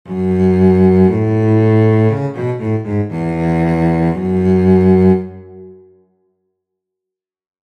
Detaché
A súa interpretación é notas separadas pero sen ningún tipo de acento, un son lixeiro.
detache.mp3